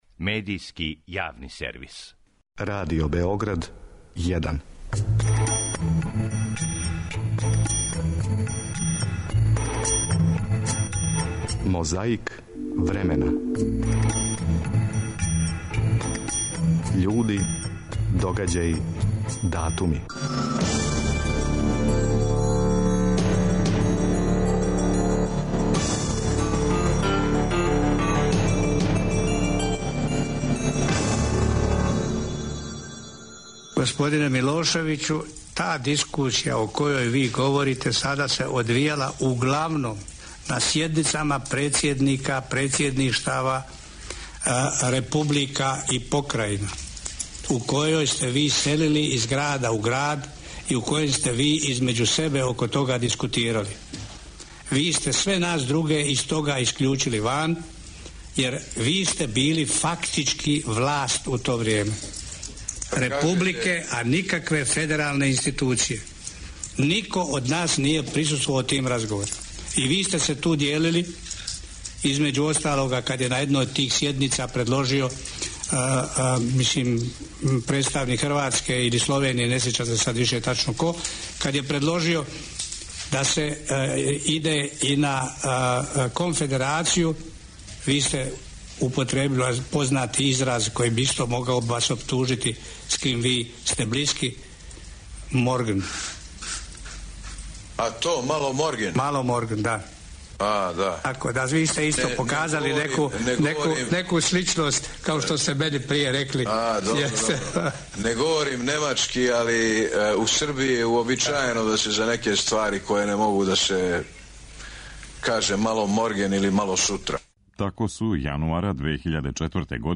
Овонедељну борбу против пилећег памћења почињемо разговором између сведока Анте Марковића и оптуженог Слободана Милошевића, у Хагу, јануара 2004. године.